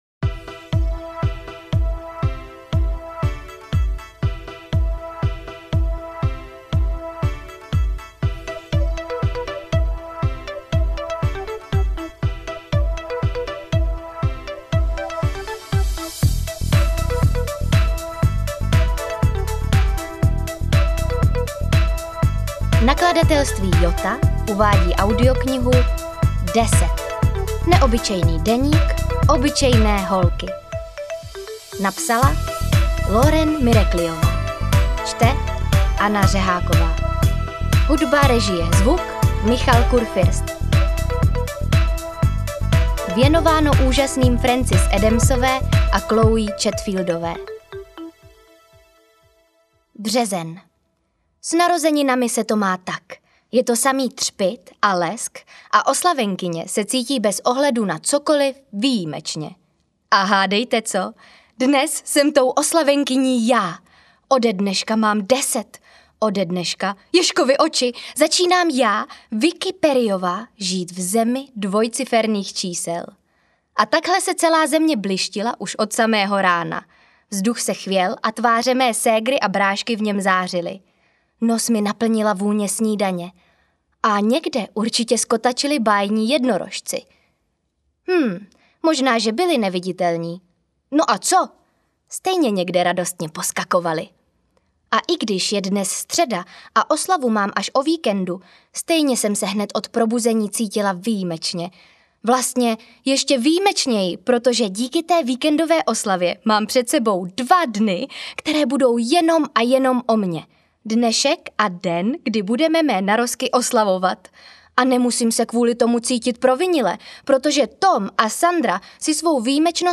AudioKniha ke stažení, 13 x mp3, délka 5 hod. 39 min., velikost 309,5 MB, česky